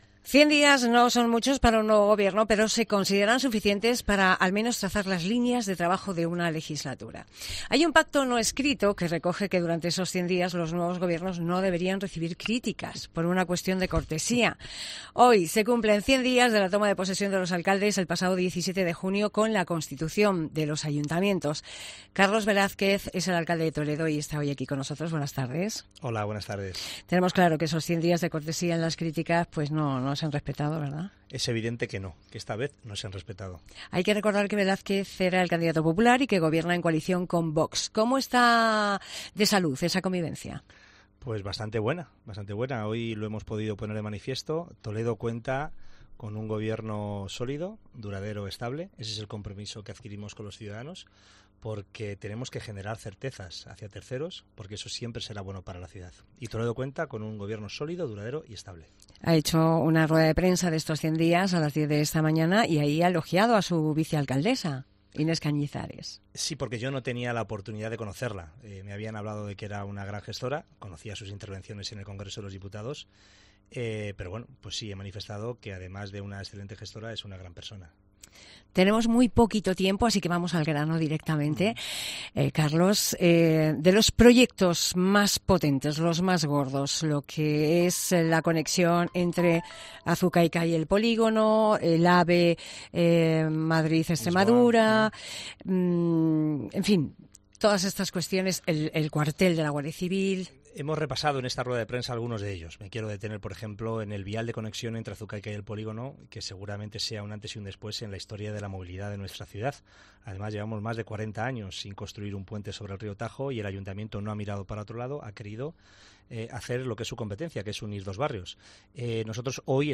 Entrevista al alcalde de Toledo, Carlos Velázquez, en los primeros 100 días de gobierno